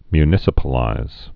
(my-nĭsə-pə-līz)